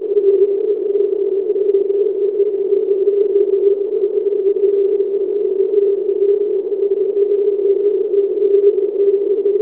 The compression ratio was 32, input frequency of 800 Hz with a 100 Hz filter, output frequency of 400 Hz with a 50 Hz bandpass filter. I subsequently converted the CRUNCH output WAVE to 8k samples per second, 4 bit coding, which reduces file size drastically but produces some quality degradation. However, if you strain a bit and listen through a few loops of the file, it should be possible to hear the LEK identifiers in there.
CRUNCHed slow CW at -30 dB